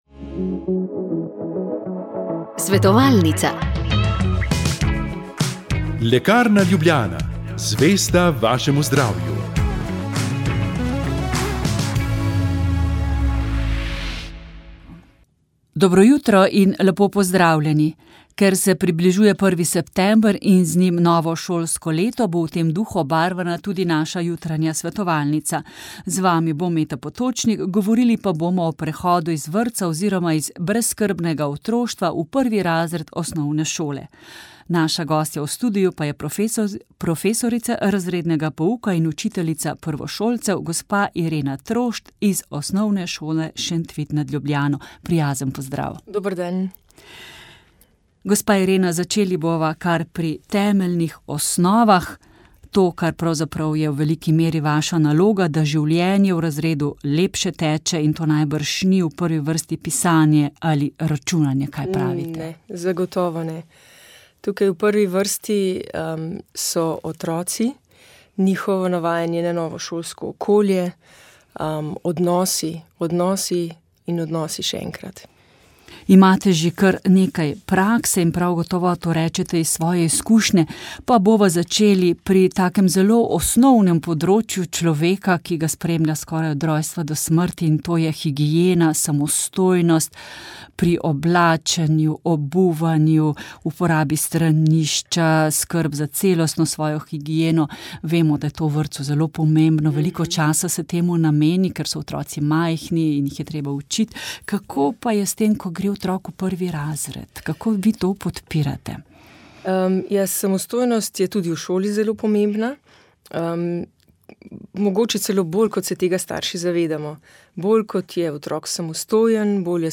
Molili so bogoslovci.